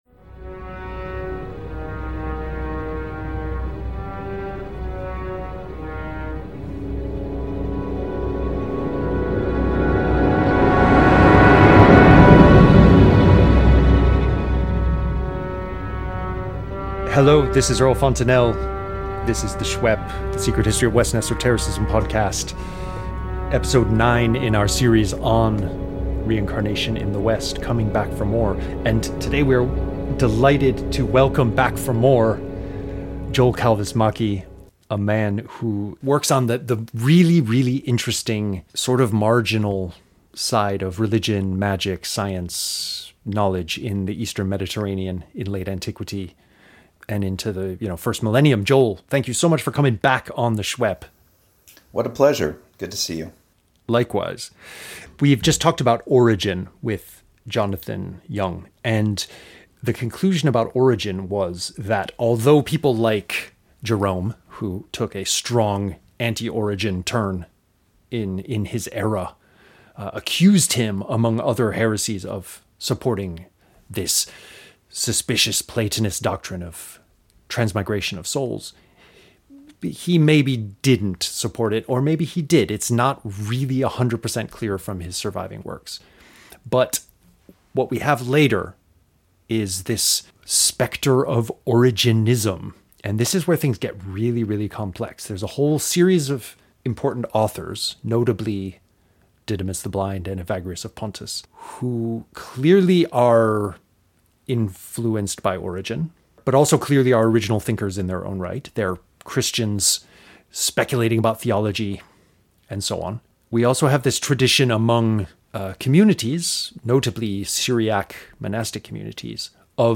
Interview Bio